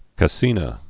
(kə-sēnə)